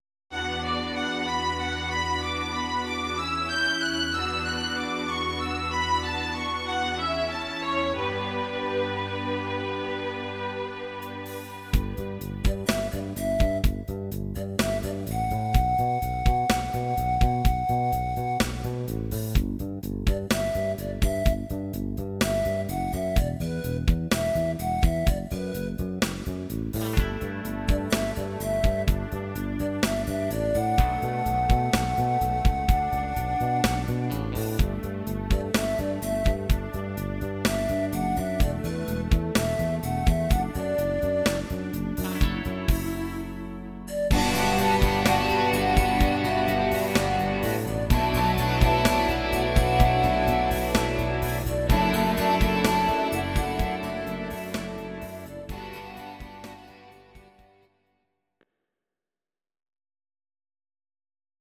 Audio Recordings based on Midi-files
Rock, 1990s